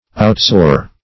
Outsoar \Out*soar"\